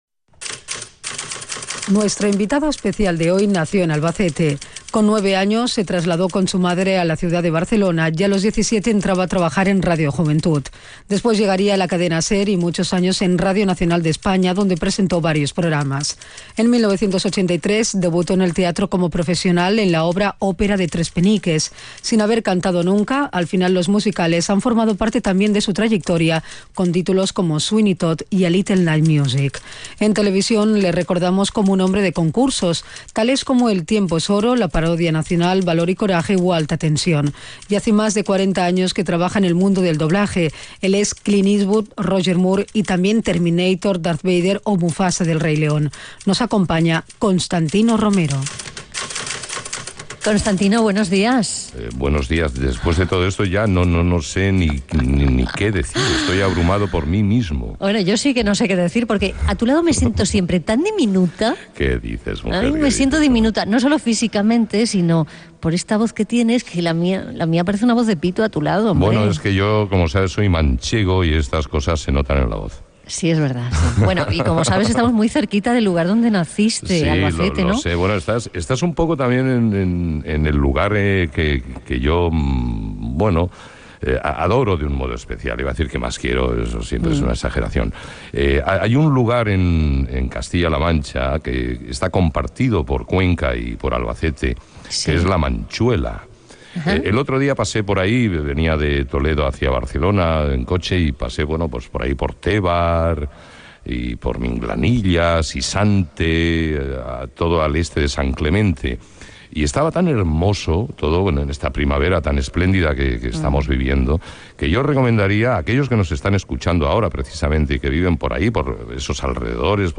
Programa fet des de Conca. Perfil biogràfic professional i entrevista al presentador Constantino Romero. S'hi parla de La Manchuela, Castilla y la Mancha Televisión, la seva trajectòria profressional, els concursos de televisió, el doblatge